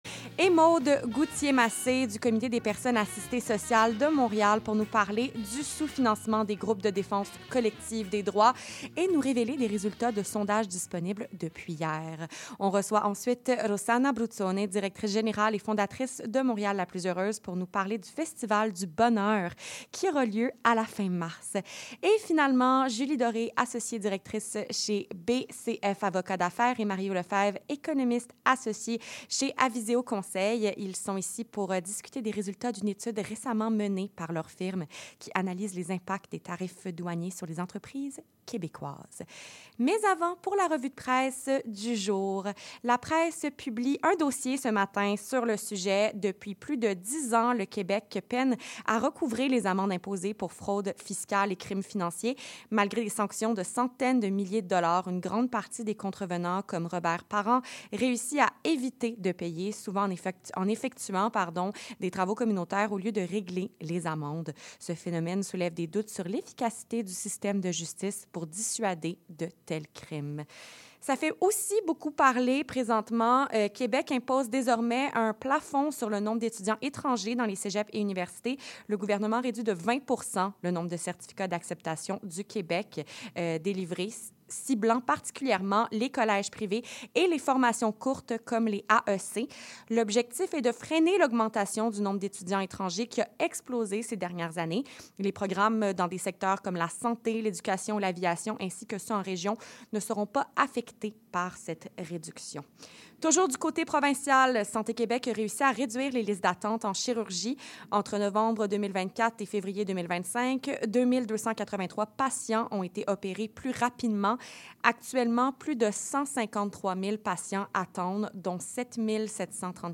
Conférence de presse: reconnaissance de la défense collective des droits
Le Regroupement des organismes en défense collective des droits (RODCD) était en conférence de presse ce matin, accompagné de trois organismes membres. À plus ou moins un mois du dépôt du budget provincial, ces derniers ont dénoncé les impacts du sous-financement endémique des groupes en défense collective des droits (DCD).